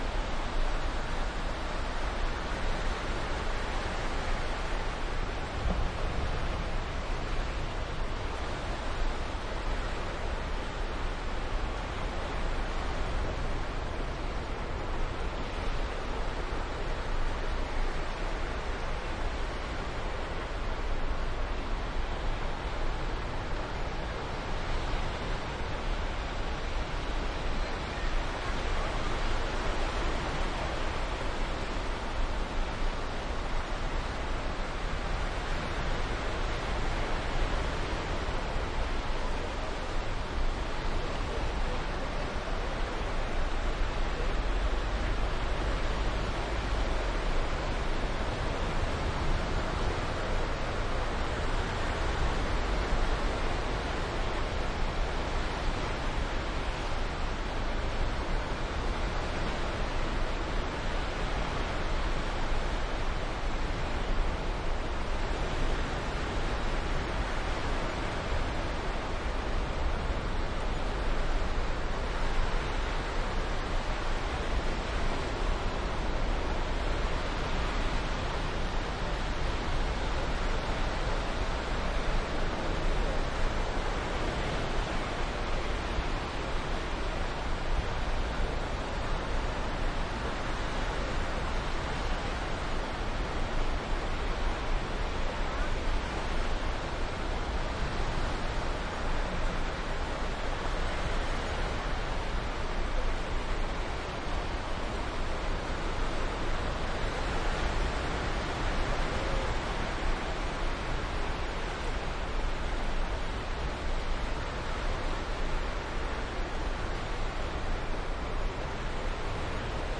海 " 海滩
描述：这张录音是在2008年6月使用iriver ihp120录音机完成的，配备了辉煌的摇滚盒软件，Shure WL 183麦克风和FEL电池前置放大器。 北贝里克附近的海域距离很近。
Tag: 海滩 海岸 海岸 海浪 海岸 海岸 海洋 苏格兰 海边 现场记录 冲浪